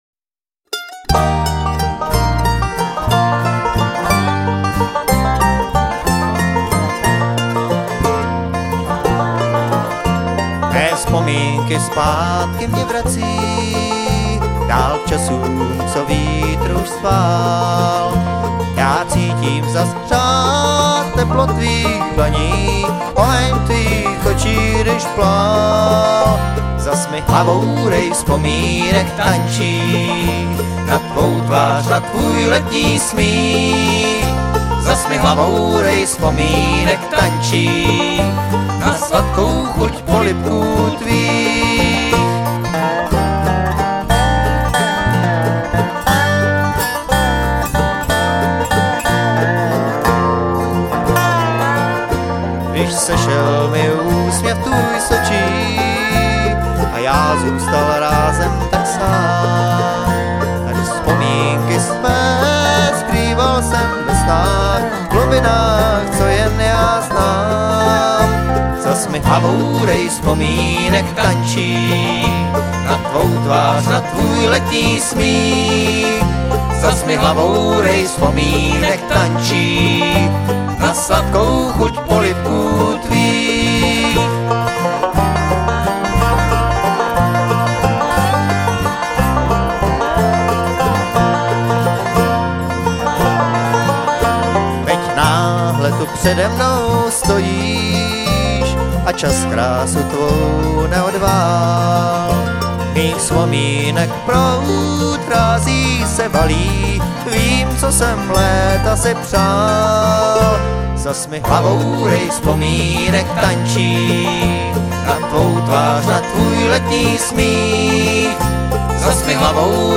Guitar
Banjo
Mandolin
Dobro
Electric Bass